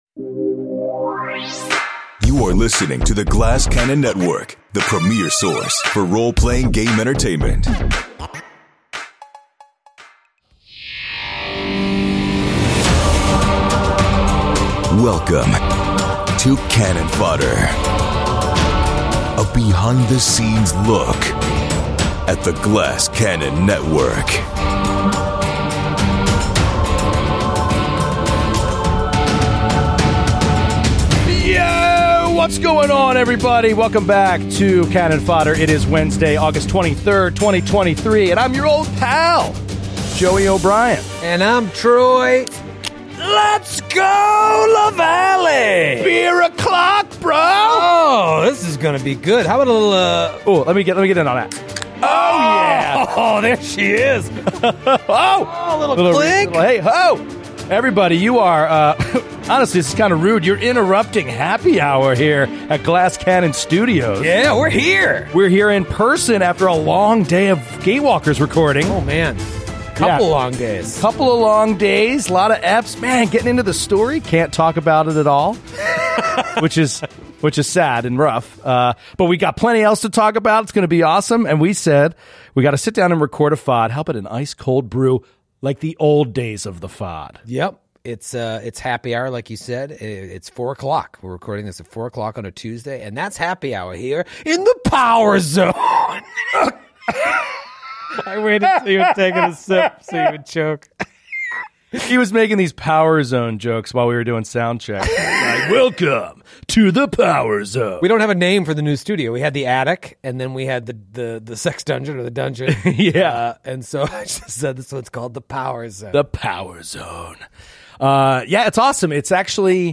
do a little Fod in-person at GCN Studios! They discuss GCP Campaign II Session Zero, exciting news about changes to the GCN Twitch channel, and the plans for this year's Glass Cannon Christmas Party. In We Are Stupid, they debate whether or not you can use your reaction before your first turn of combat.